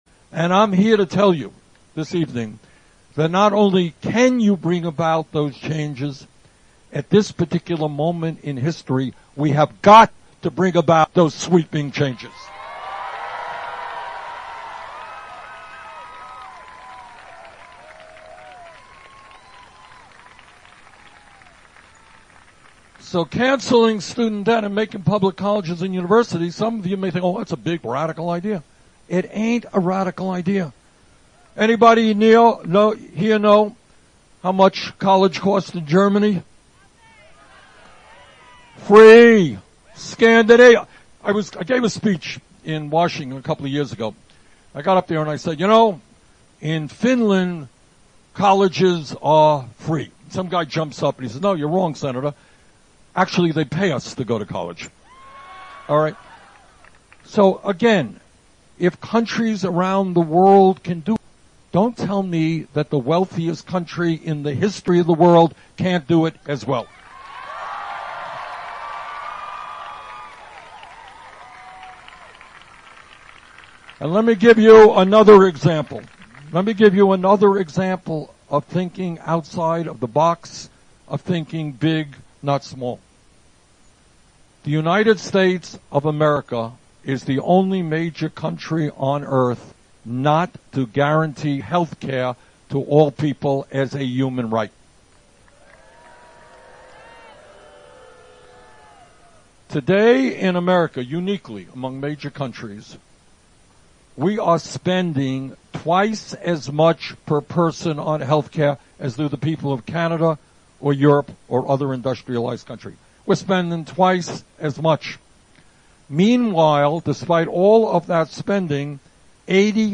AUDIO SPEECH by Bernie Sanders